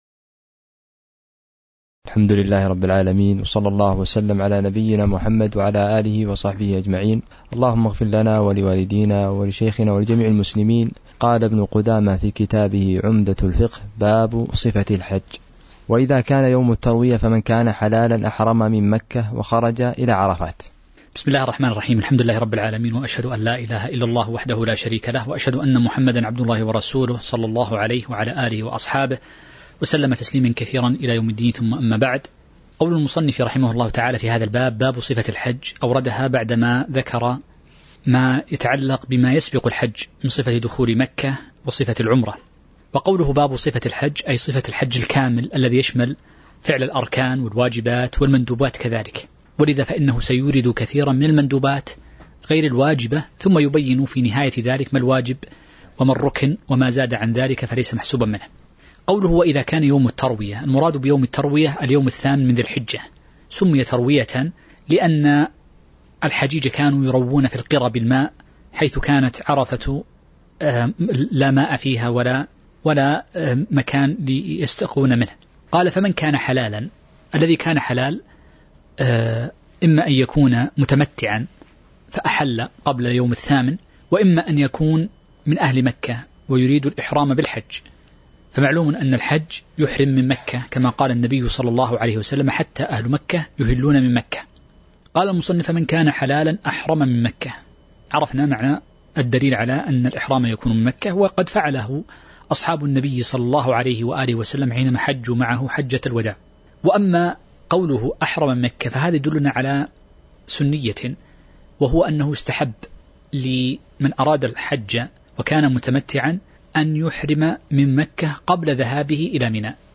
تاريخ النشر ٣ ذو الحجة ١٤٤٣ هـ المكان: المسجد النبوي الشيخ